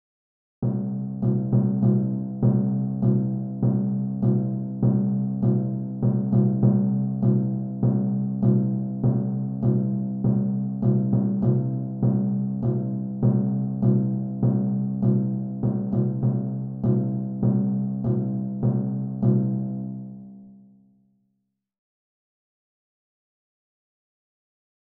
Ascolta questa sequenza ritmica, dedotta dall'audio precedente, e trascrivila sul pentagramma.
Si tratta di otto misure da 4/4.
sequenza_ritmica.mp3